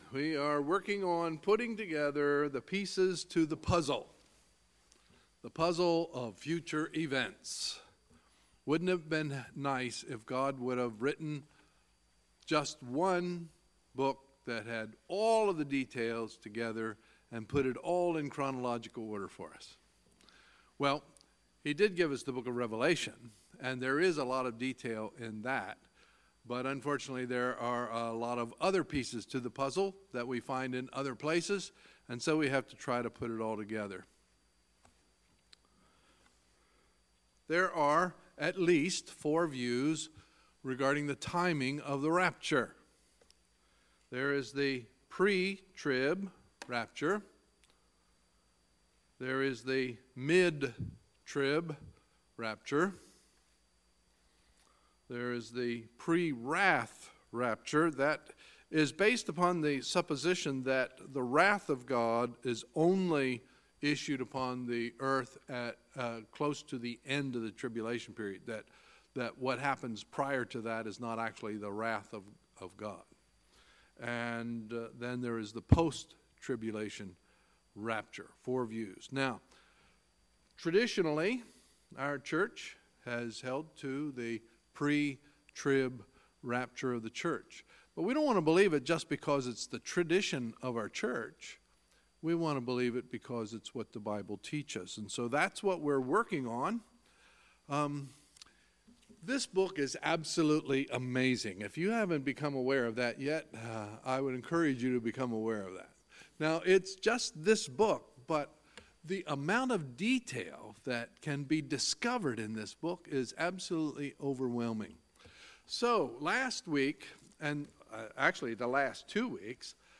Sunday, May 13, 2018 – Sunday Evening Service